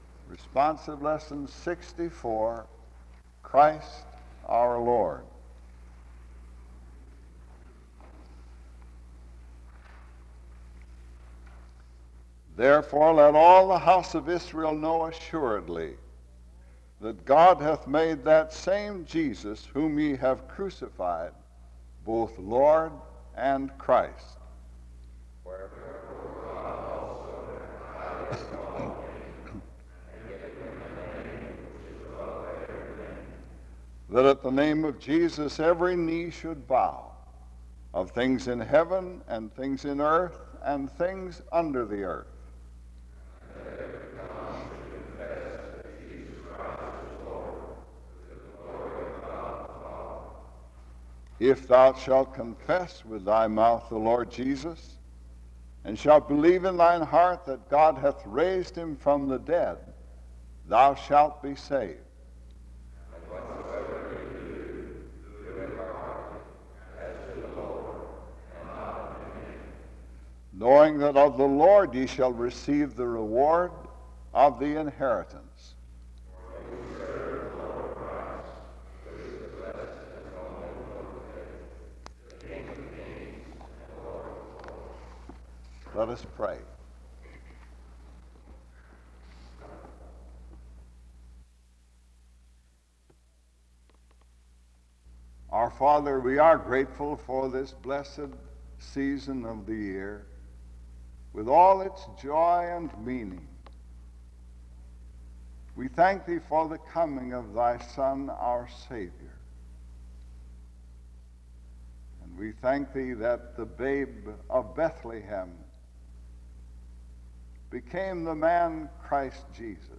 En Collection: SEBTS Chapel and Special Event Recordings SEBTS Chapel and Special Event Recordings - 1960s Miniatura Título Fecha de subida Visibilidad Acciones SEBTS_Chapel_R_H_Edwin_Espy_1969-12-16.wav 2026-02-12 Descargar